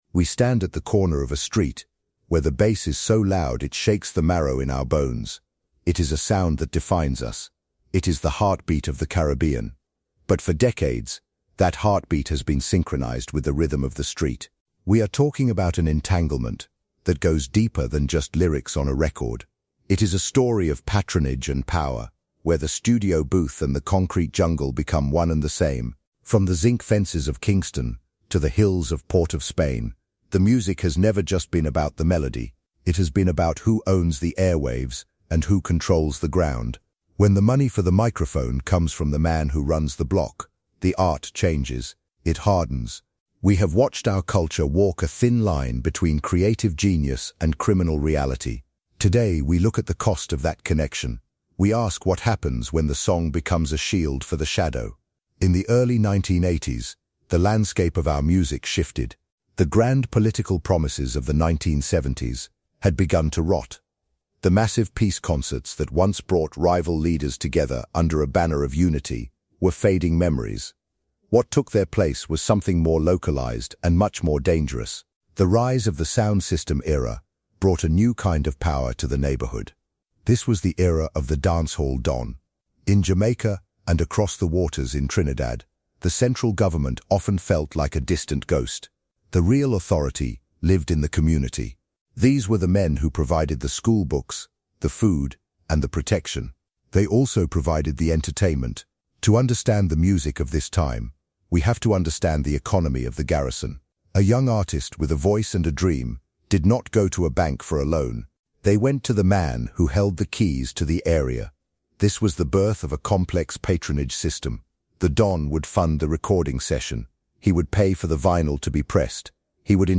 This documentary podcast episode of THE HISTORY OF THE CARIBBEAN investigates the deep-seated, systemic entanglement between the Caribbean music industry and the power structures of the street.